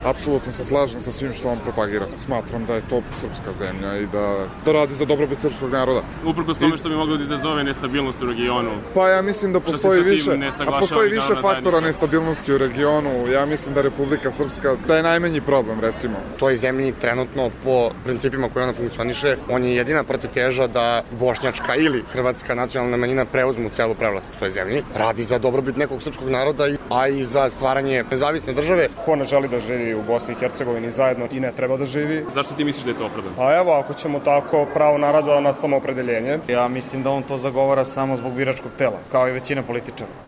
Milorad Dodik je na skupu na beogradskom Pravnom fakultetu dočekan frenetičnim aplauzom studenata dok je, ko zna koji put, pričao kako je za BiH najbolje da se mirno raspadne. Čime Dodikove nacionalističke ideje privlače dobar deo mladih u Srbiji?